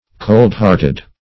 coldhearted \cold"heart`ed\, cold-hearted \cold"-heart`ed\, a.